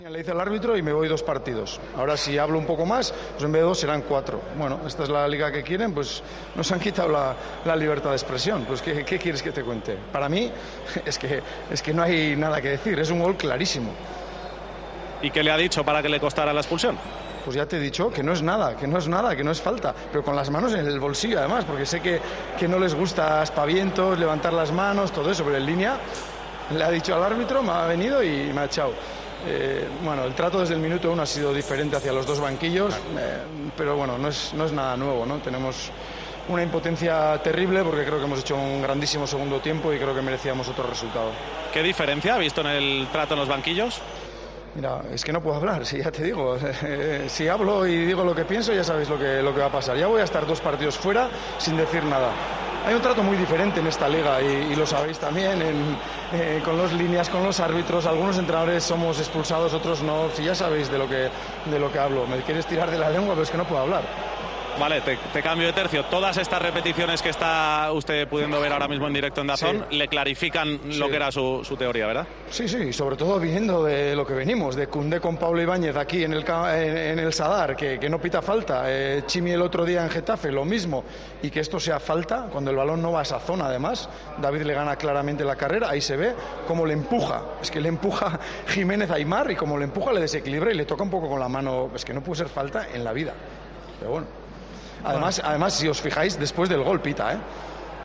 AUDIO: El entrenador de Osasuna se quejó en DAZN del gol anulado a su equipo en la derrota ante el Atlético de Madrid y que provocó su expulsión.